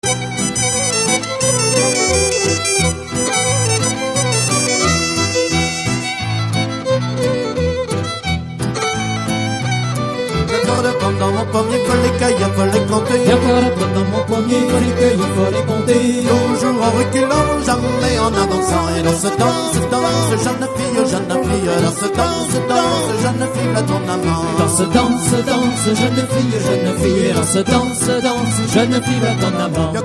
Musique : Traditionnel
Interprètes : Klaskerien
Origine : Bretagne
Danse : Rond de Loudéac
Chant par le groupe Klaskerien sur l'album Hé Danses et Dañs !... en 1999 (Extrait Nozbreizh).